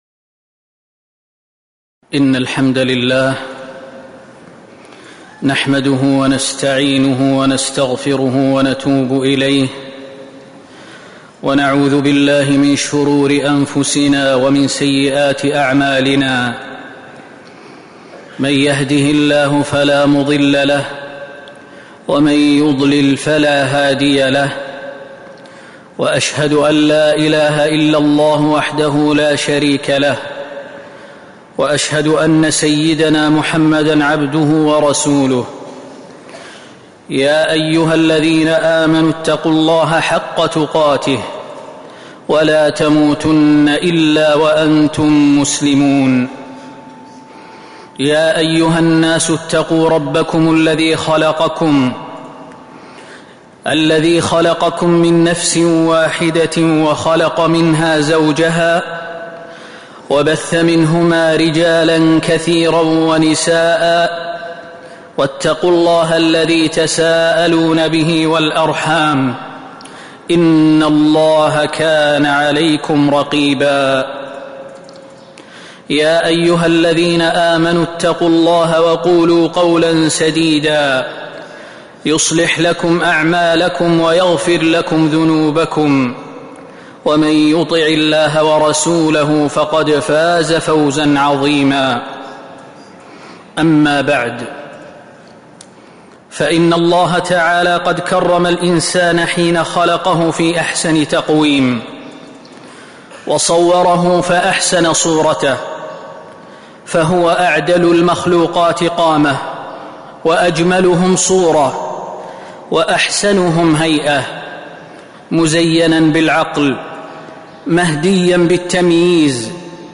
تاريخ النشر ١٠ شوال ١٤٤٥ هـ المكان: المسجد النبوي الشيخ: فضيلة الشيخ د. خالد بن سليمان المهنا فضيلة الشيخ د. خالد بن سليمان المهنا من أسباب صلاح القلوب